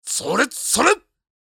太鼓ボイス
/ F｜演出・アニメ・心理 / F-85 ｜演出用ボイス